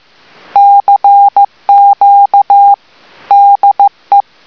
LA TELEGRAPHIE, LE MORSE (CW)
15 mots/mn